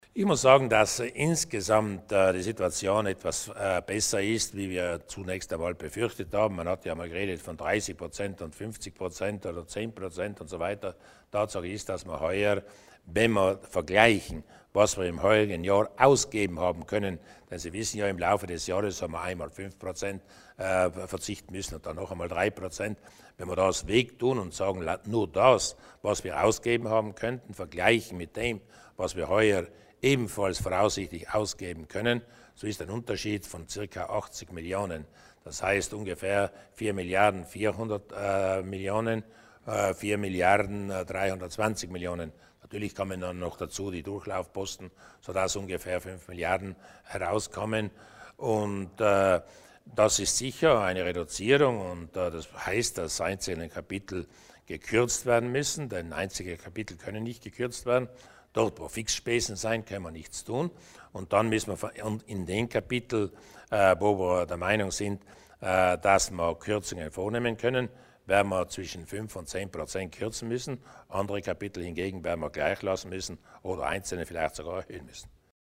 Landeshauptmann Durnwalder berichtet über die ersten Daten zur Bilanz 2013